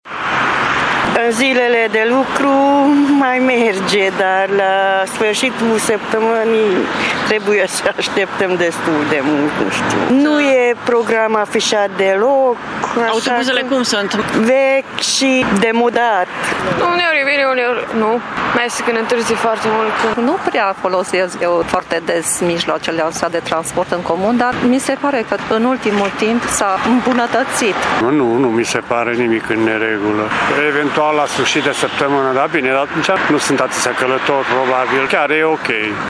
Târgumureșenii s-au obișnuit cu autobuzele vechi și mulți nu mai au deloc așteptări: